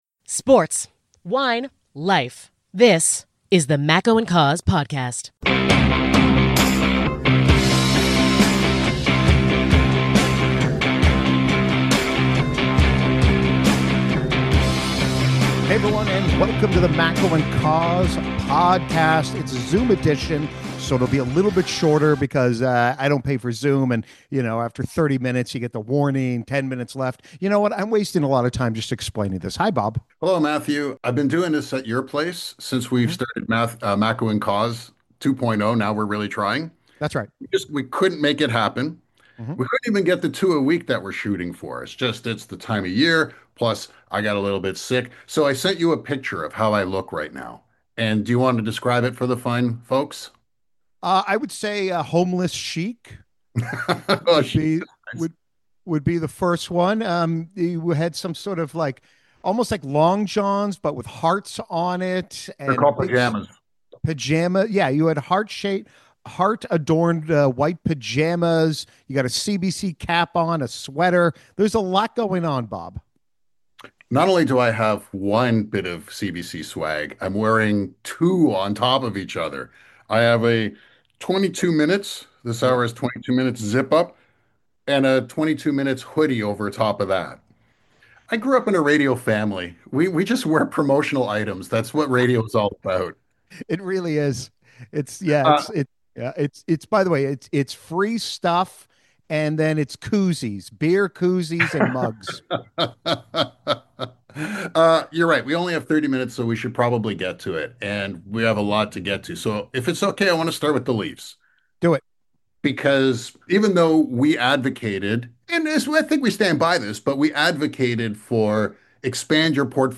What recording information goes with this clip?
It's a slightly shorter Zoom version of the Pod.